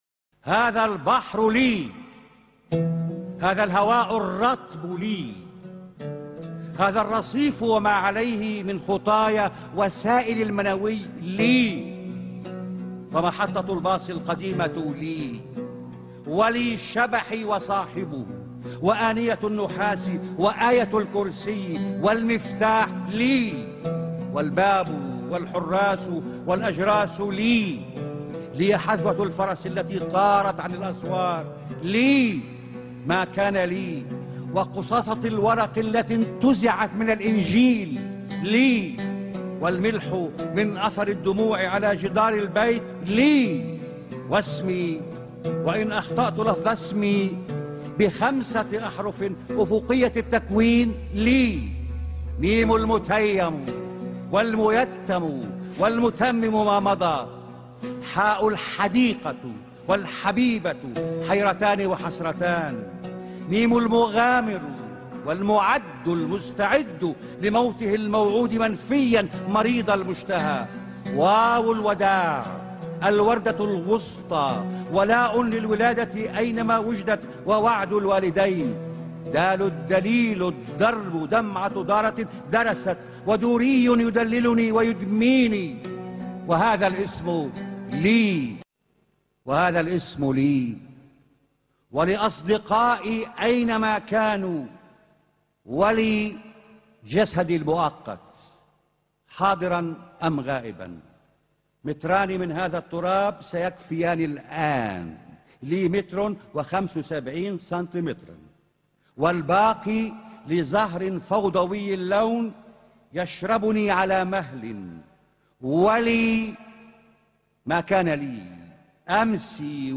في الذكرة الثامنة لوفاته، تحية متواضعة لشاعر حفر في الضمير العربي نقشا يذكر بأرض خلفت للسلام وما رأت يوما سلاما. التقرير الصوتي أعلاه